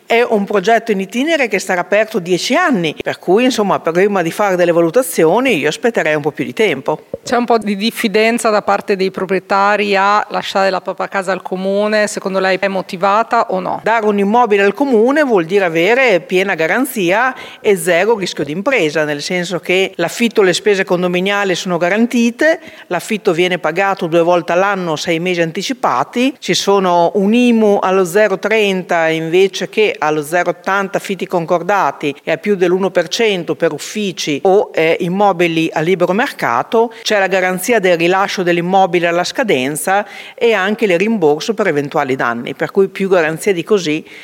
Sentiamo l’assessore Francesca Maletti: